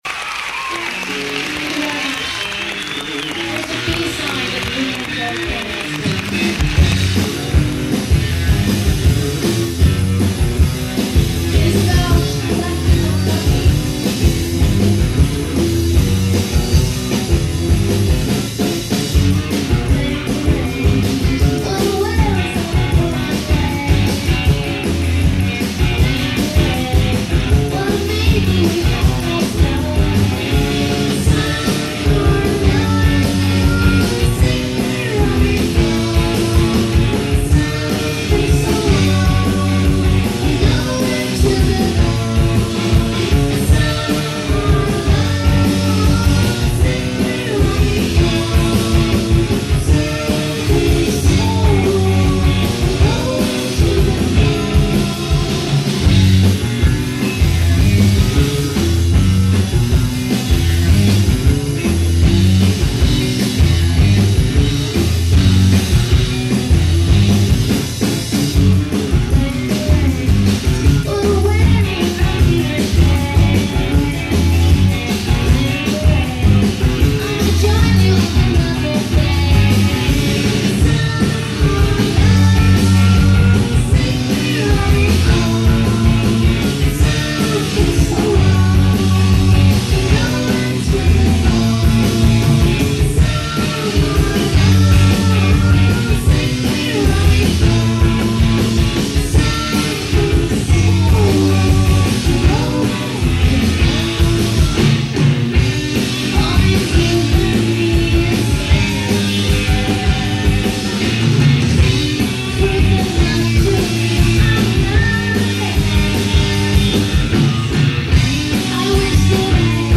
Koln Theater